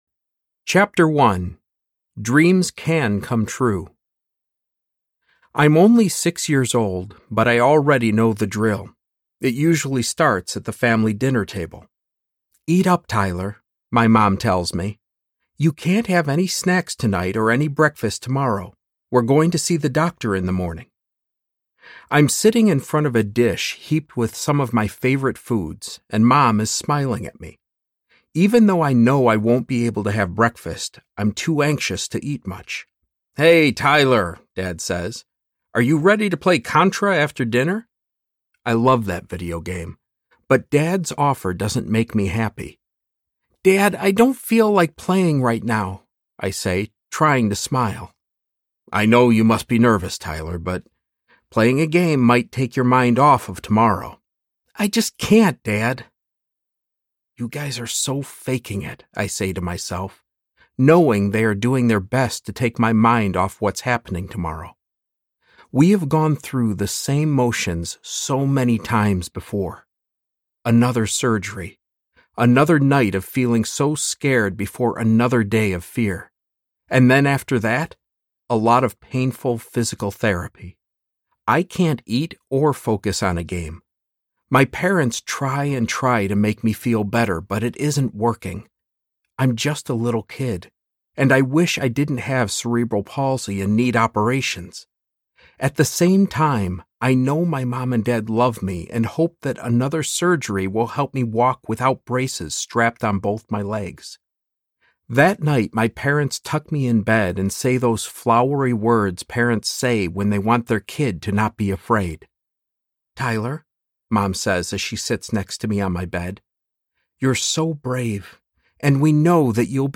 No Such Thing As Can’t Audiobook
Narrator
5.9 Hrs. – Unabridged